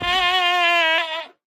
Minecraft Version Minecraft Version 1.21.5 Latest Release | Latest Snapshot 1.21.5 / assets / minecraft / sounds / mob / goat / screaming_death2.ogg Compare With Compare With Latest Release | Latest Snapshot
screaming_death2.ogg